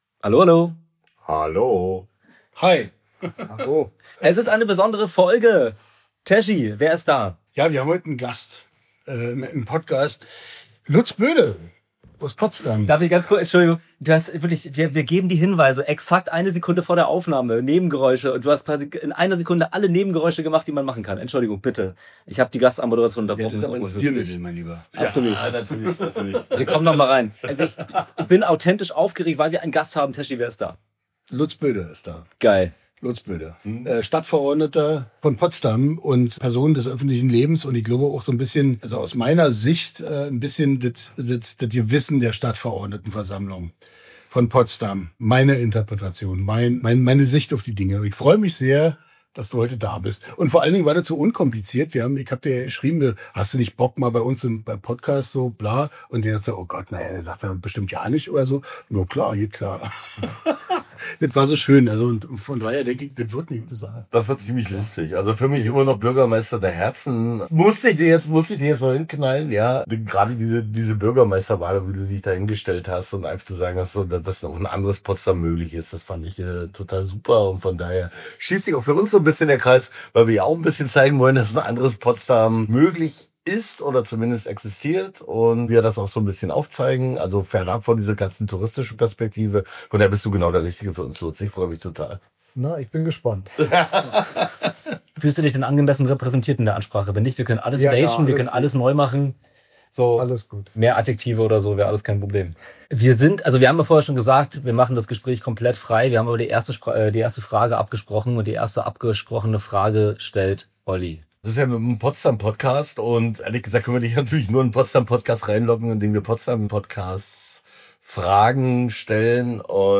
Darum hier die ersten 65 Minuten eines Gesprächs, das wir schon ganz bald fortsetzen, dann mit Storys bis in die Gegenwart.